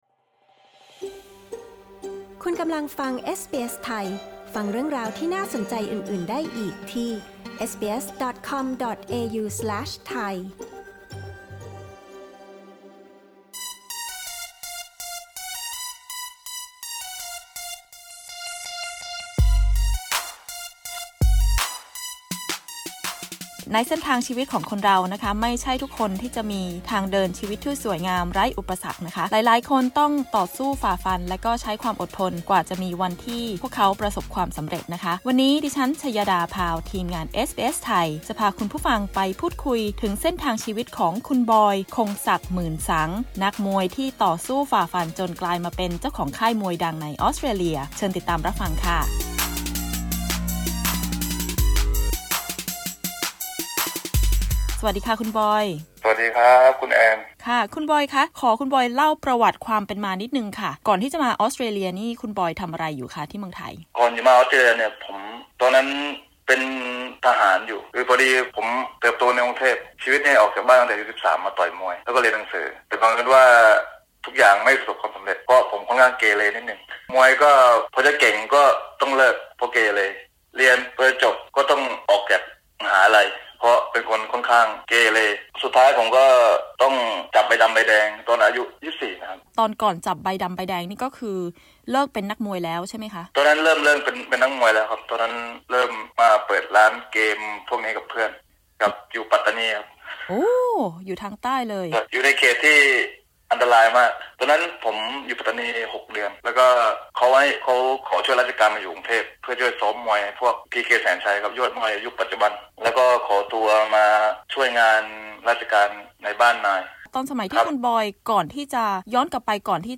กดปุ่ม 🔊ที่ภาพด้านบนเพื่อฟังสัมภาษณ์เรื่องนี้